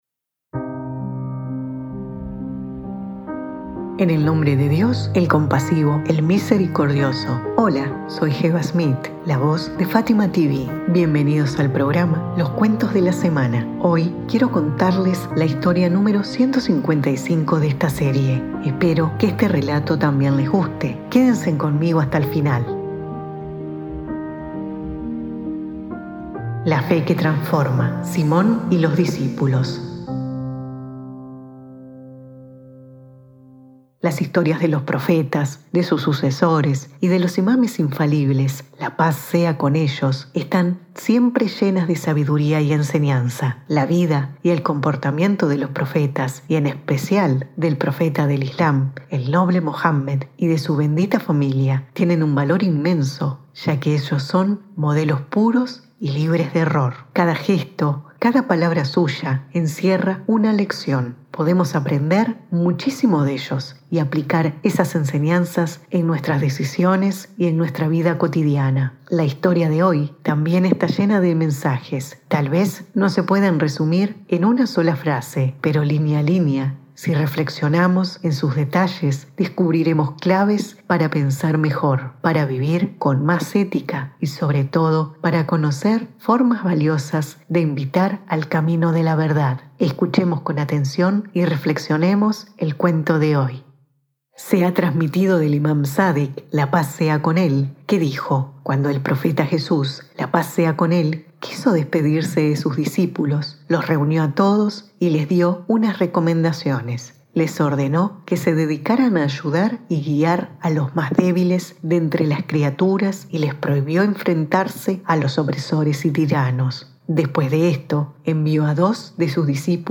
🎙 Locutora: